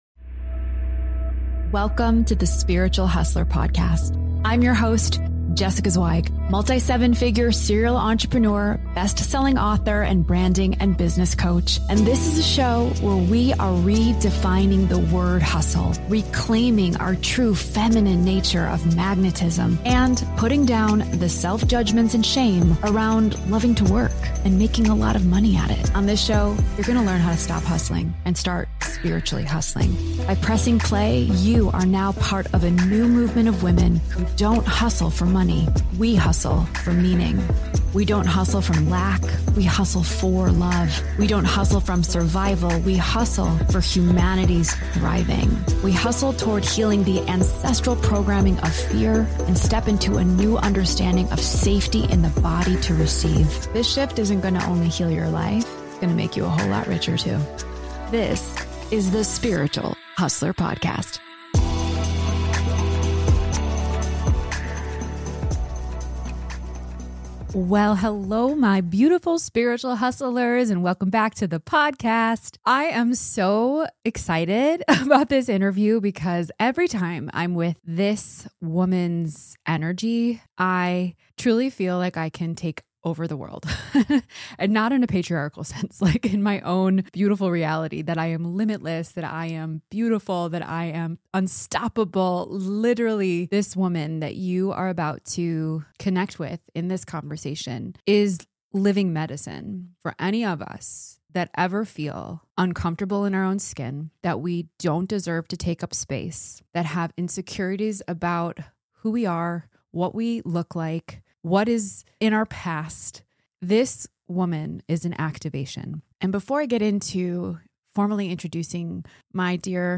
In this powerful and deeply activating conversation